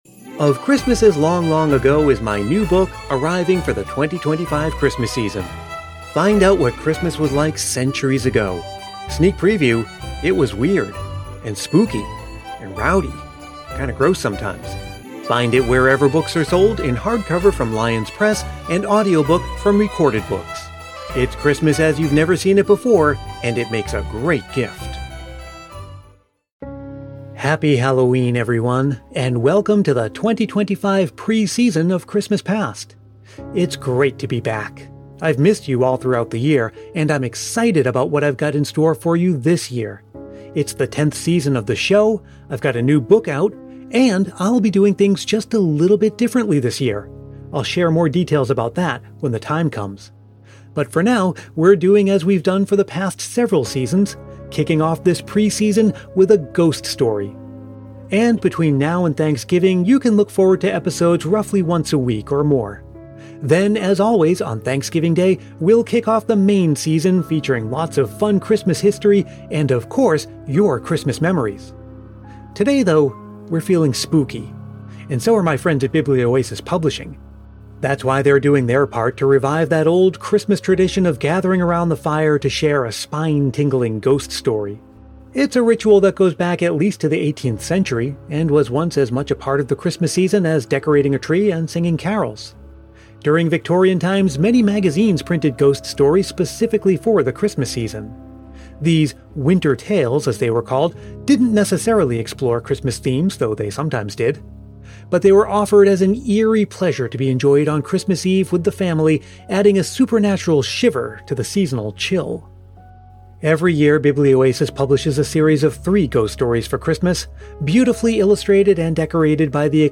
Today, I’m narrating Rosemary Temperley’s short story, “The Mistress in Black.”
Ambient Background Pad